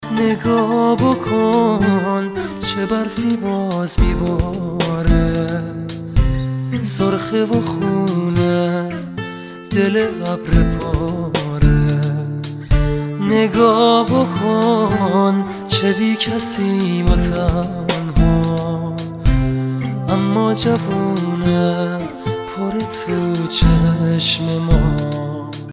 رینگتون زیبا و رمانتیک و با کلام